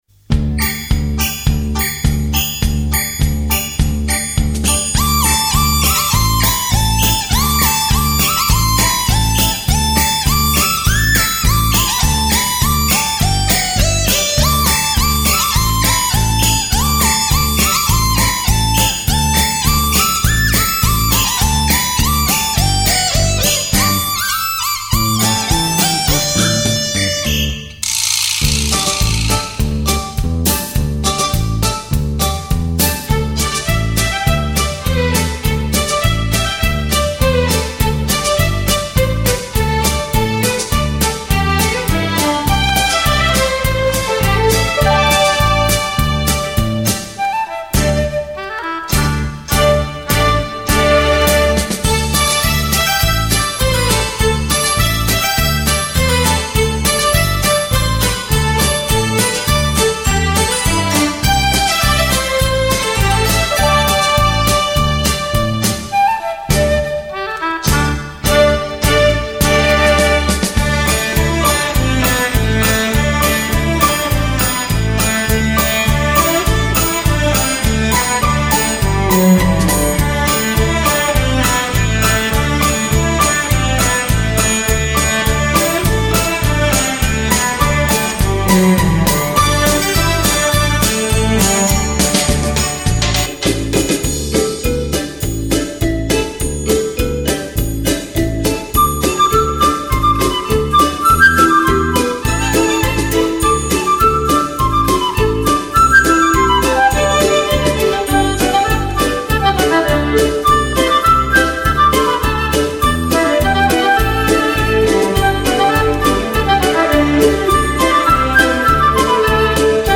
专辑格式：DTS-CD-5.1声道
本专辑是用器乐演奏来表现大众所喜闻乐见的舞曲节奏音乐，音质上乘佳作，是您在聆听中美的享受，更令爱舞者翩然起舞。